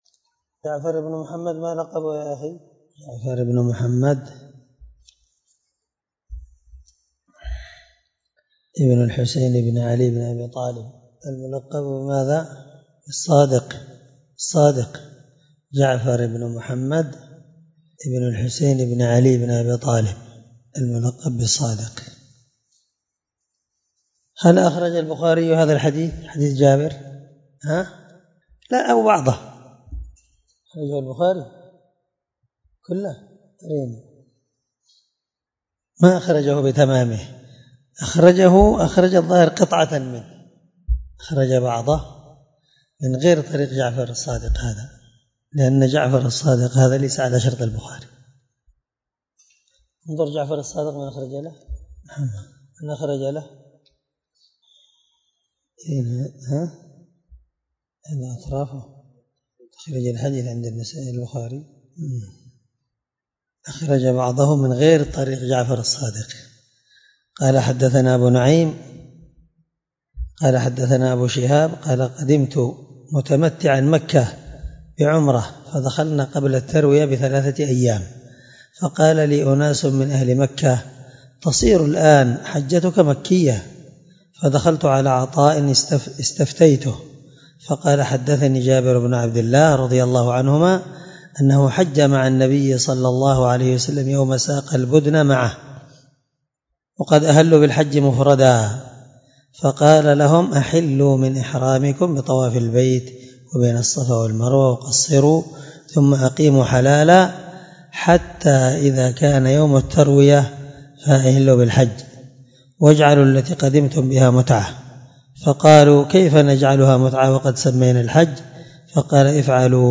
736الدرس 21من شرح كتاب الحج حديث رقم(1219-1220) من صحيح مسلم